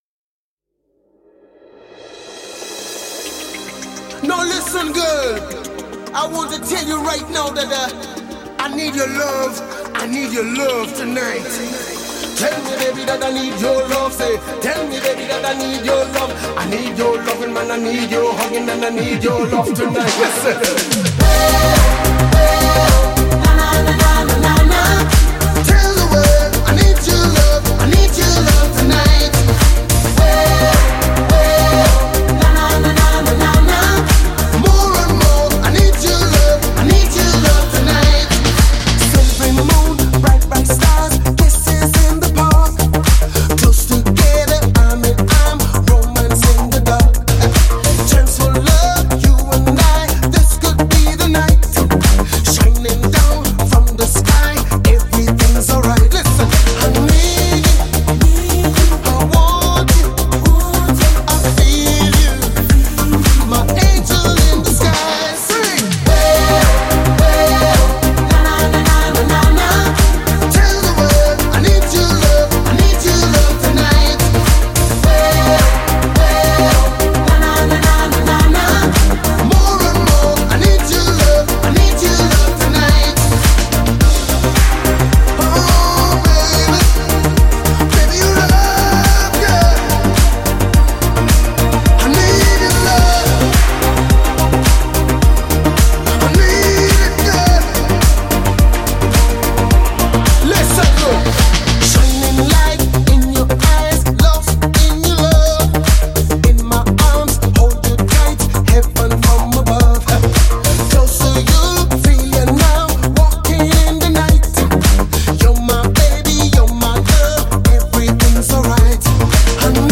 Mixtest / alter Kommerzdiscokram in Richtung Nudisco / Synthwave...
Variante: Was ich sehr geil finde ist dieses knusprige seidige, was da im Master kommt und die Präsenz des Mixes. Im A/B Vergleich klingt mein rough dann irgendwie doch etwas matt.